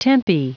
Prononciation du mot tempi en anglais (fichier audio)